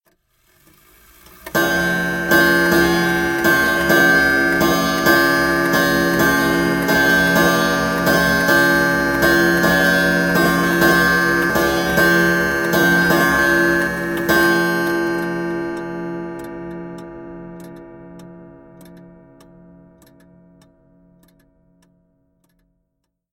Crazy Chime
Tags: clock